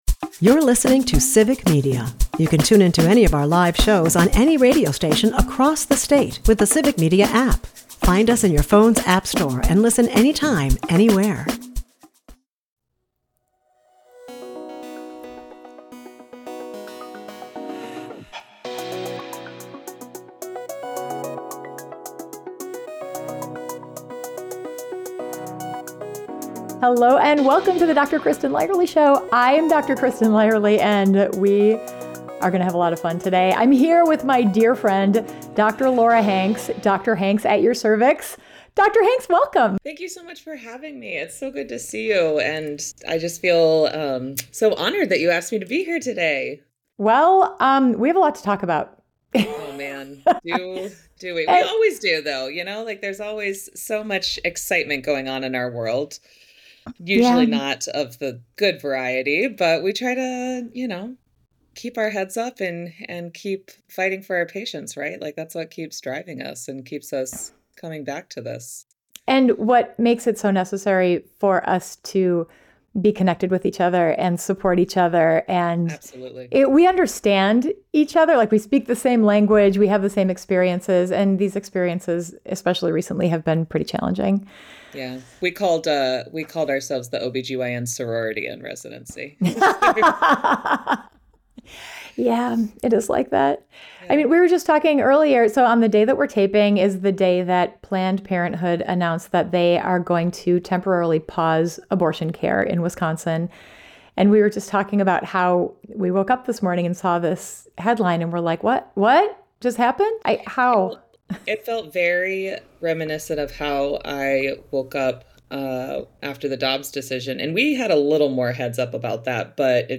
Is Tylenol really safe during pregnancy? Is birth control safe? Should I exercise during pregnancy? They're breaking it all down with evidence-based answers, real talk, and plenty of laughs.